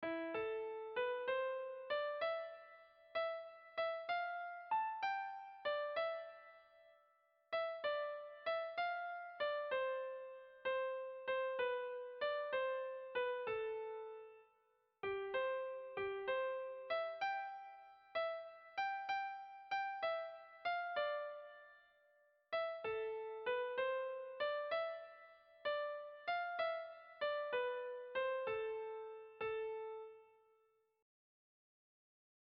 Sentimenduzkoa
Kazkabarra
Zortziko txikia (hg) / Lau puntuko txikia (ip)
ABDE